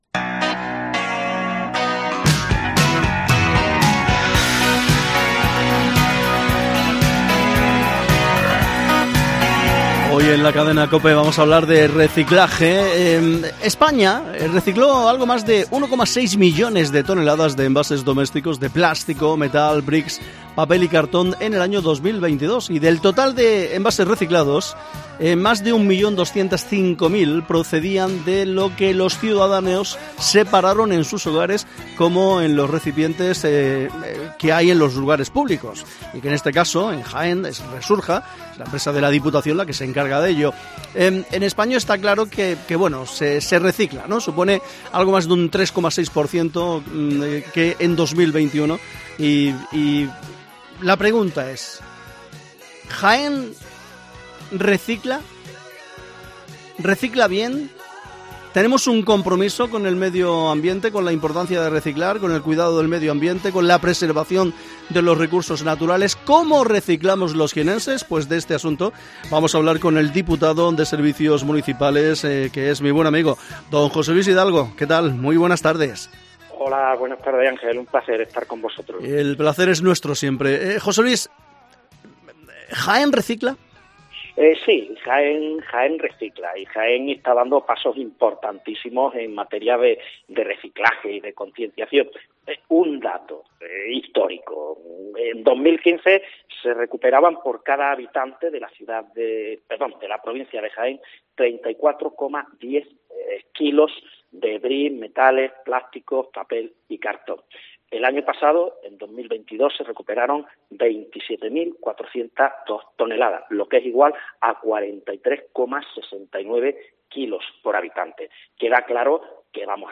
Hablamos de reciclaje con el diputado de Servicios Municipales, José Luis Hidalgo